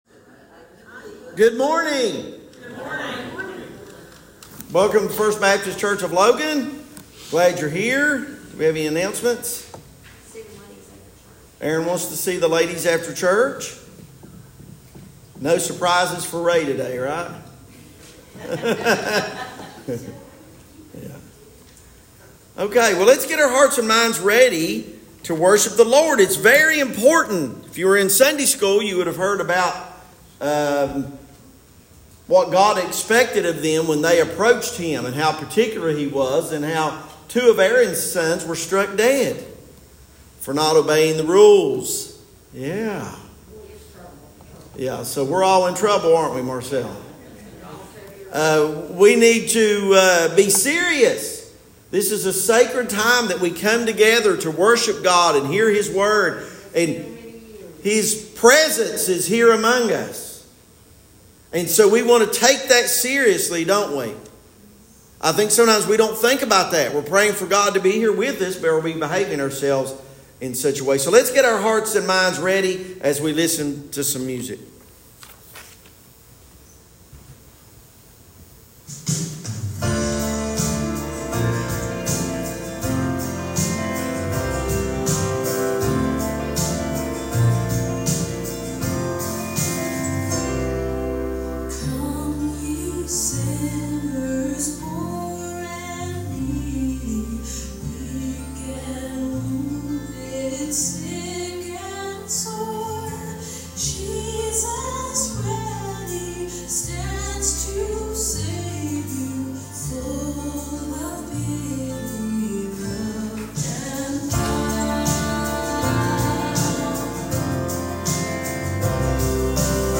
Sermons | First Baptist Church of Logan